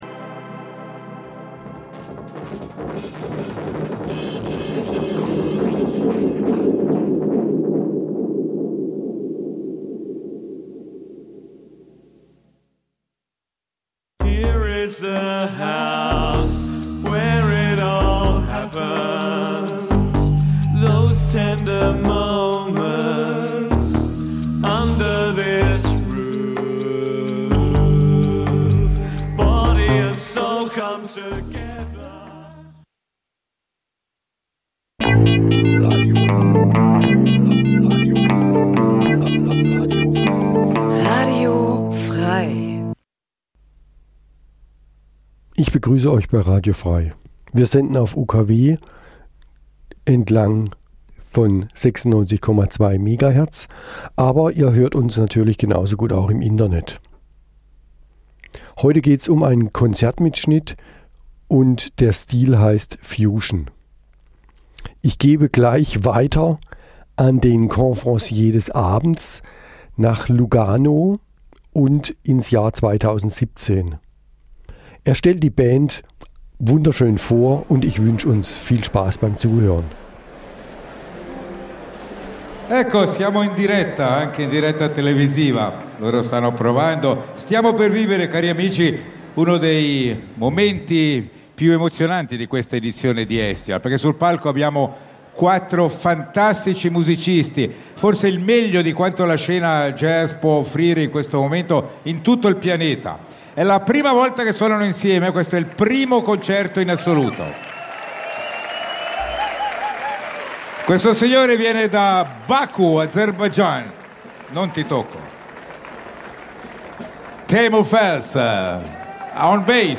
Eine Stunde Jazz Dein Browser kann kein HTML5-Audio.